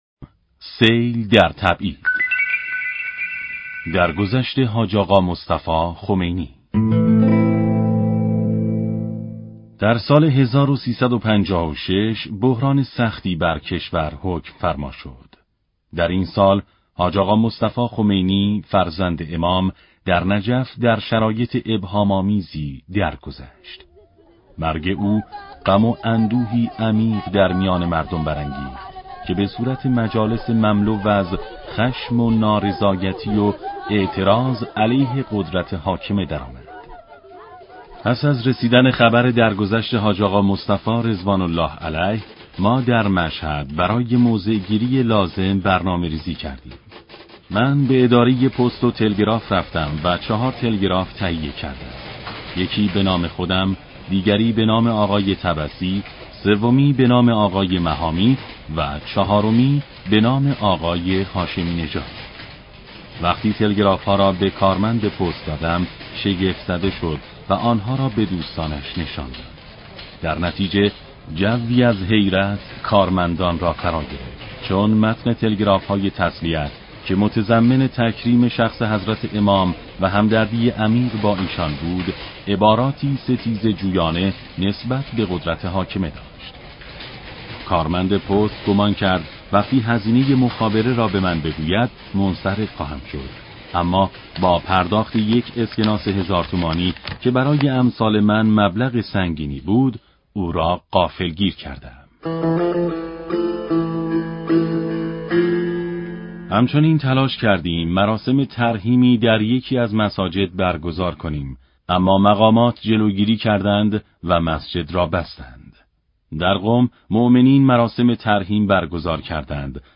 کتاب صوتی خون دلی که لعل شد